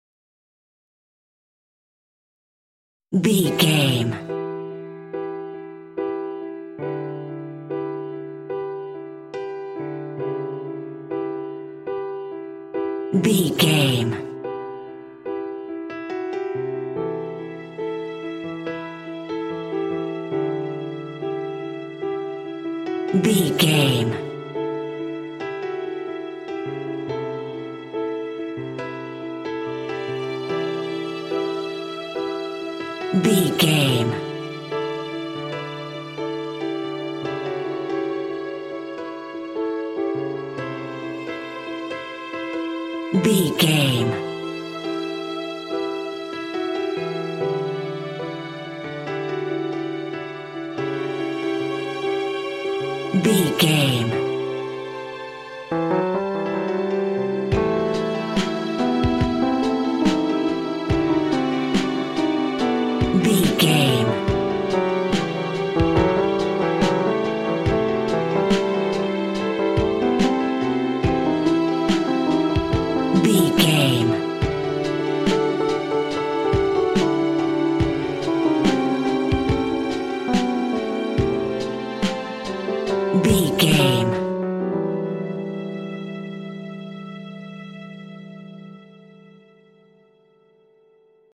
In-crescendo
Thriller
Aeolian/Minor
scary
tension
ominous
dark
suspense
dramatic
haunting
eerie
piano
strings
synthesizer
percussion
ambience
pads
eletronic